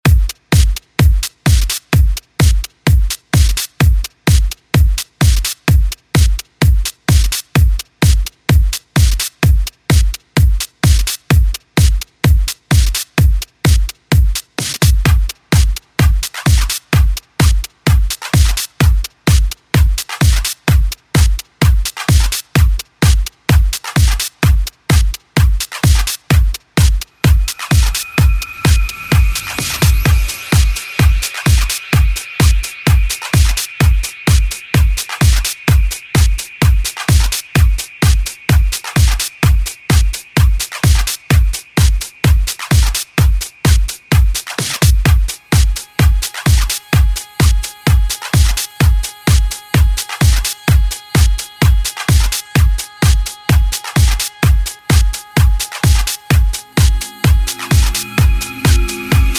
Электронная
в жанре танцевальной электронной музыки и соула